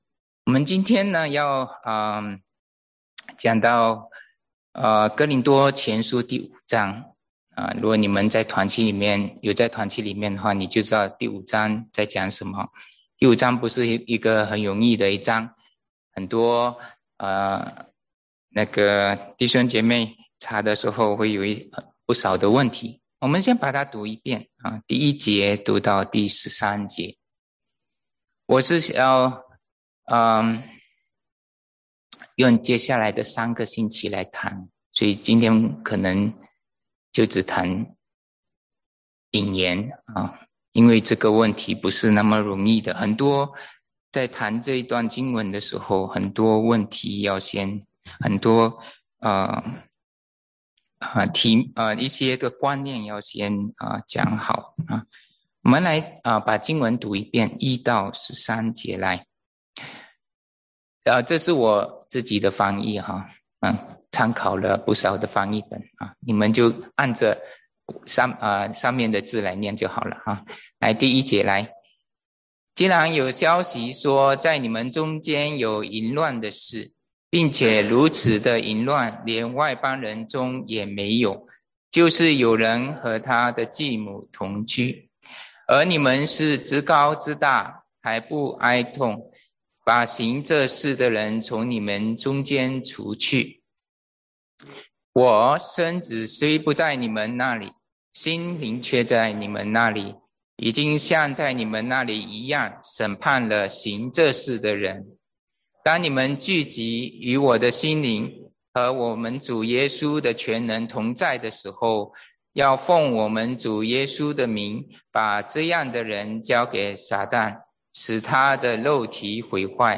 Mandarin Sermons Home / Mandarin Sermons